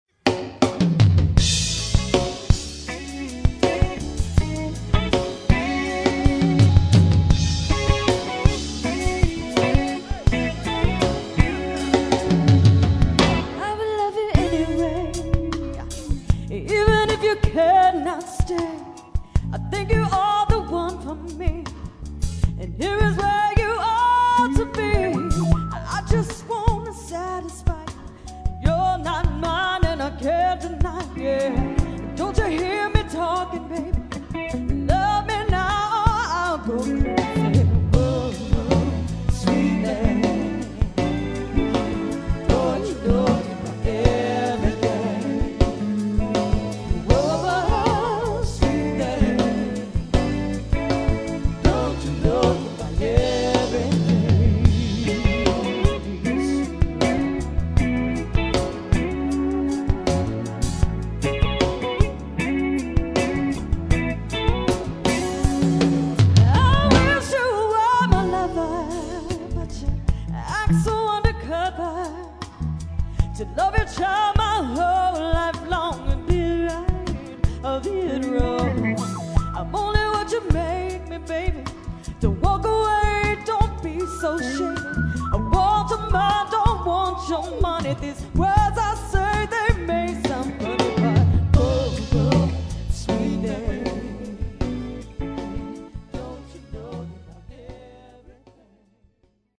funk and R&B